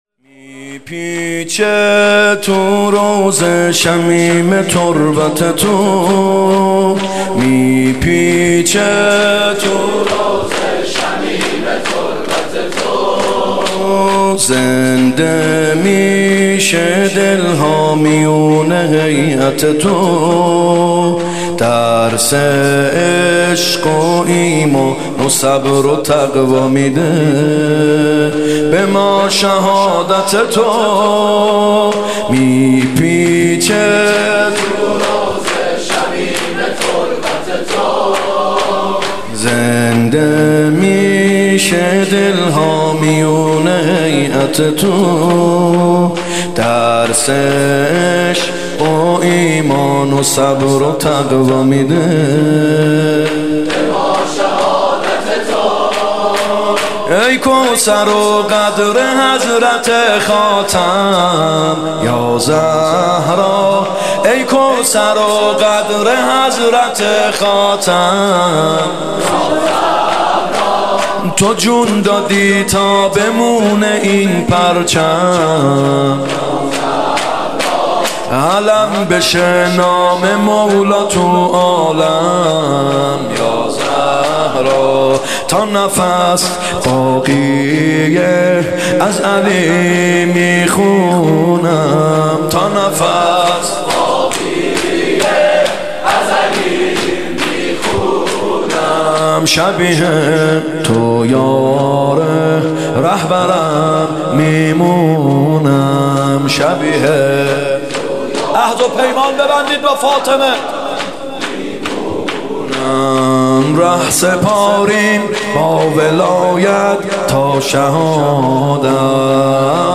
فاطمیه دوم هیئت یامهدی (عج)